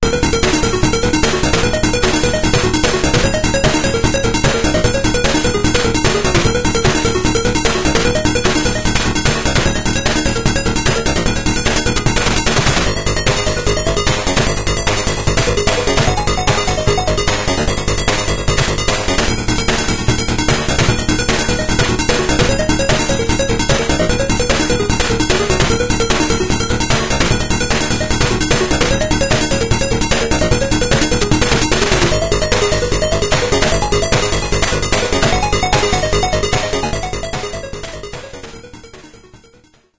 Start level tune in OGG format (1.1 Mb)